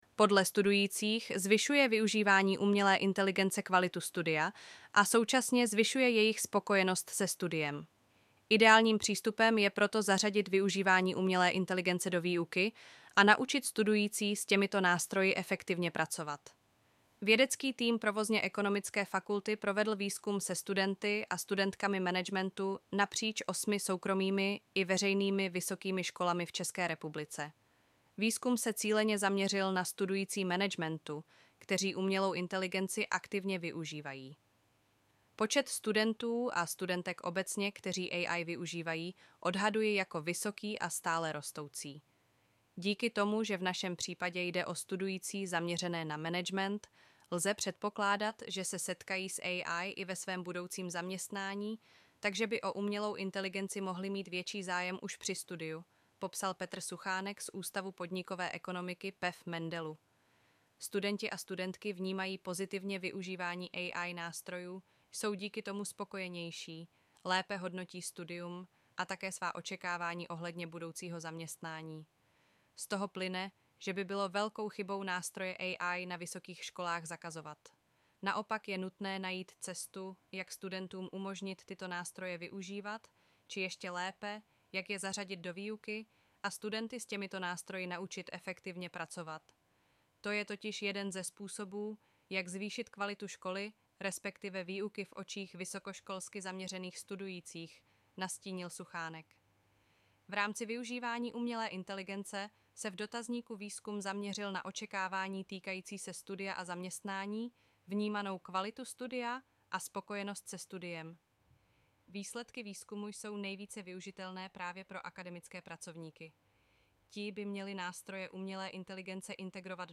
Nechte si zprávu přečíst